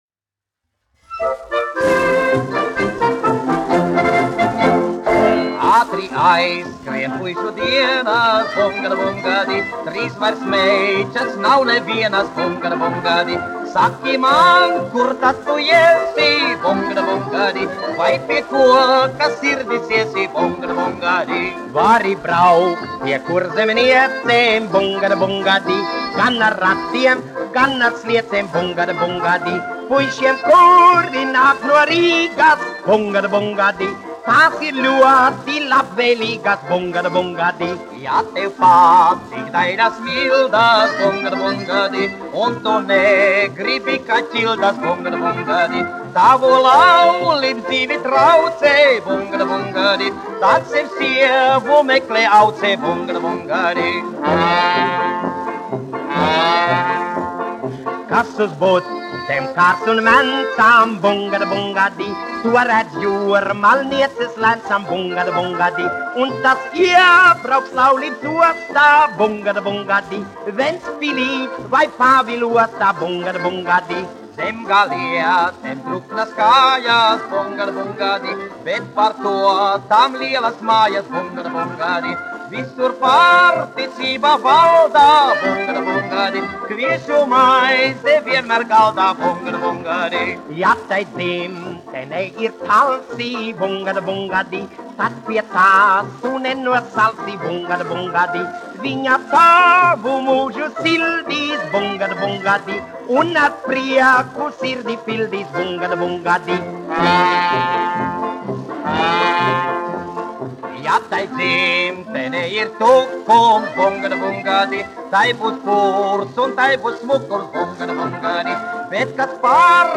1 skpl. : analogs, 78 apgr/min, mono ; 25 cm
Populārā mūzika -- Latvija
Skaņuplate